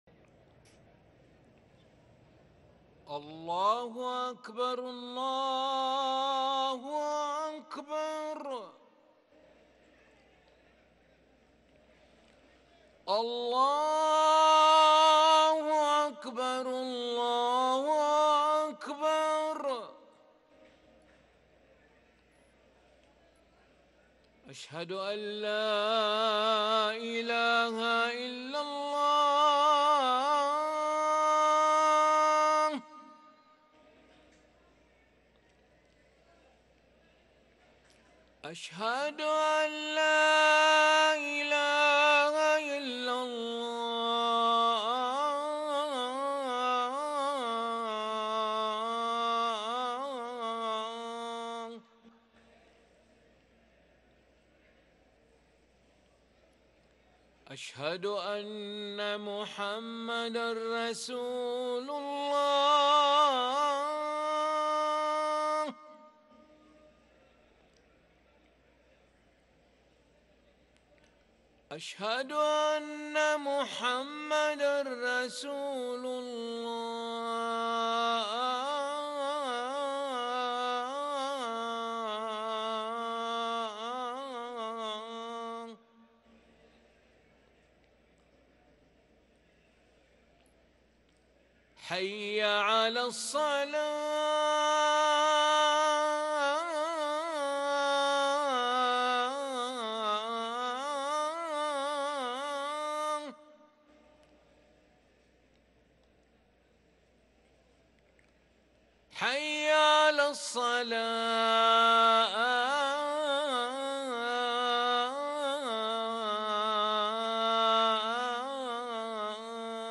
أذان العشاء للمؤذن علي ملا الأحد 5 ربيع الآخر 1444هـ > ١٤٤٤ 🕋 > ركن الأذان 🕋 > المزيد - تلاوات الحرمين